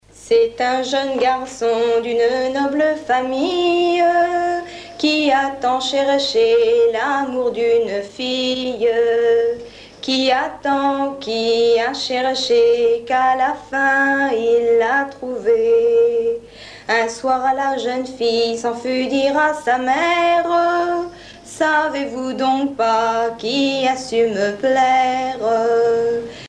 Genre dialogue
Catégorie Pièce musicale éditée